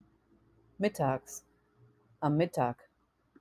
mittags / am Mittag (ca. 11-13 Uhr) (MIT-tags / am MIT-tag)